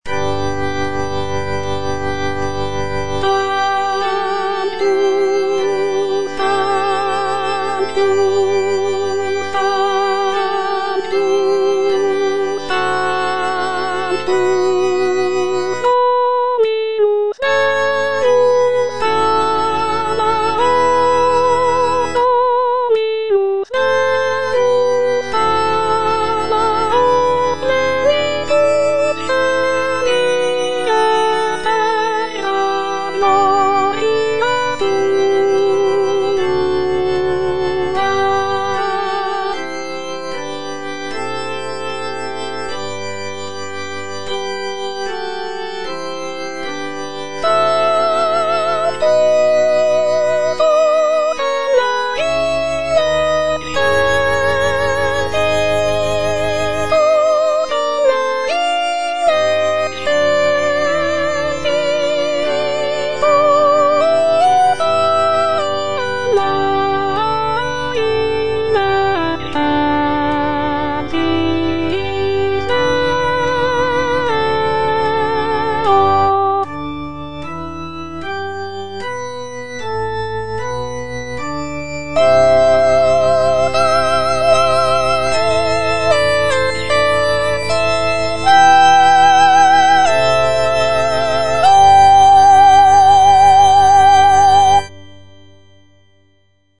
G. FAURÉ, A. MESSAGER - MESSE DES PÊCHEURS DE VILLERVILLE Sanctus (soprano II) (Voice with metronome) Ads stop: auto-stop
The composition is a short and simple mass setting, featuring delicate melodies and lush harmonies.